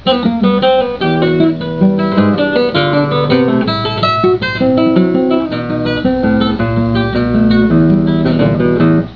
Tuning: EADGBE Key: E Minor / E major Sample: MIDI Format |
This is the famous Venezuelan Waltz No.3